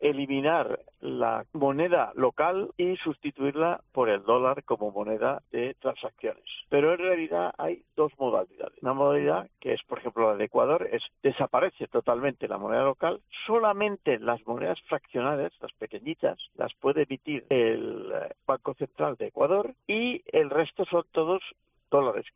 El profesor y economista